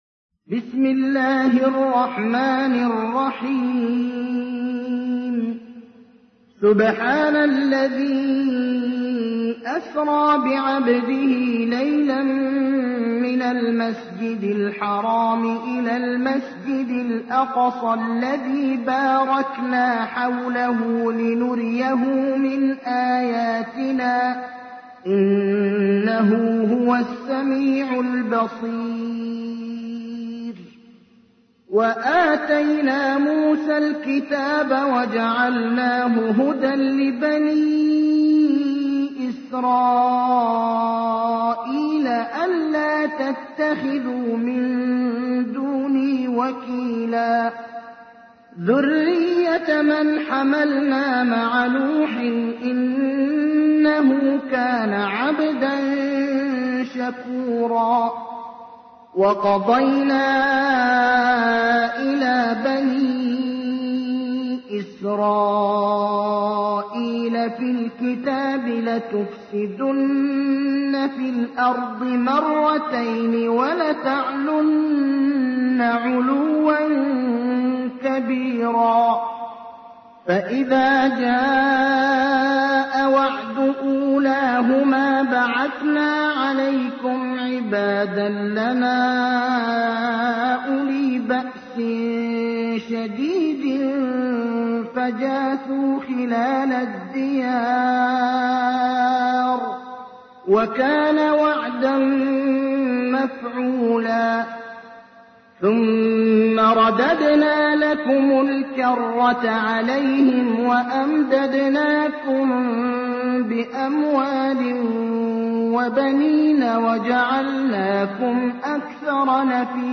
تحميل : 17. سورة الإسراء / القارئ ابراهيم الأخضر / القرآن الكريم / موقع يا حسين